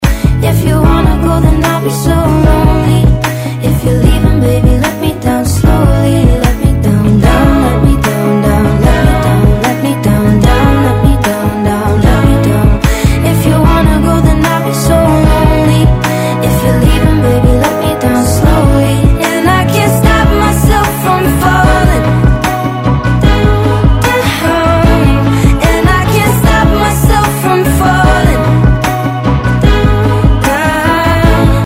Категория: Спокойные рингтоны